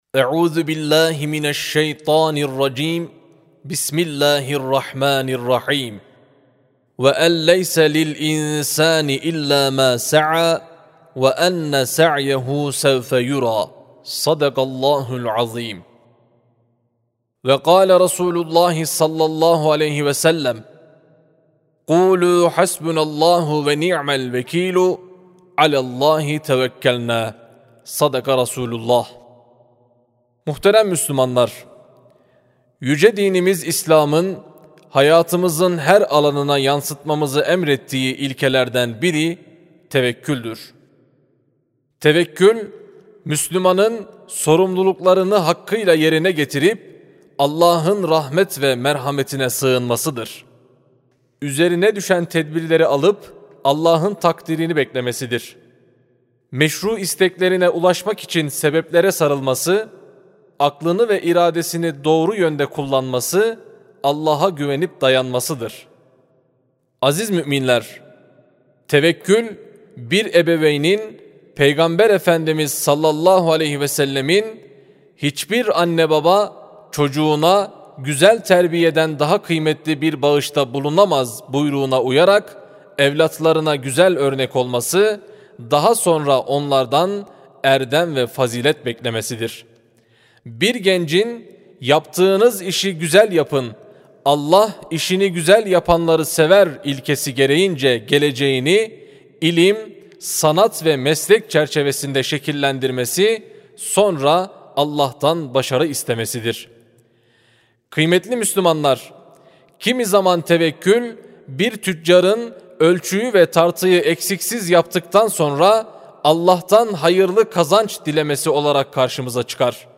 27 Mart 2026 Tarihli Cuma Hutbesi
Sesli Hutbe (Tevekkül).mp3